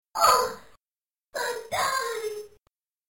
something unintelligible and...